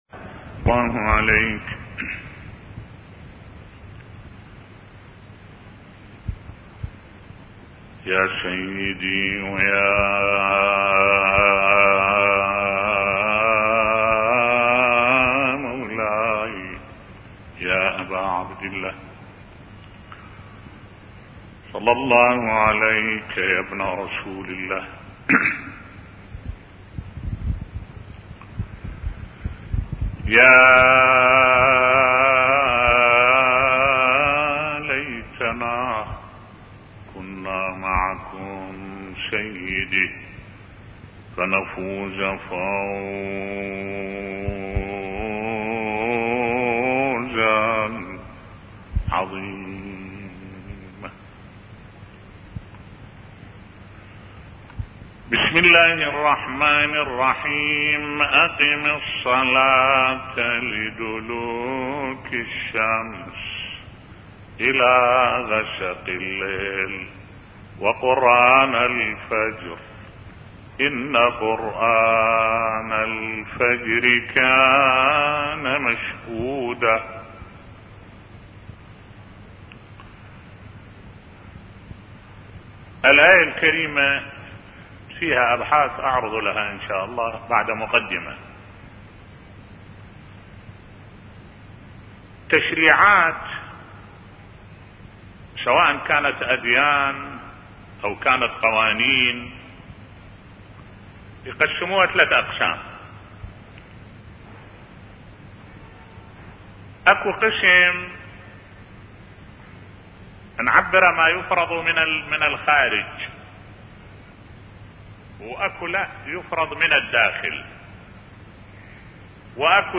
ملف صوتی اقم الصلاه لدلوك الشمس الى غسق الليل بصوت الشيخ الدكتور أحمد الوائلي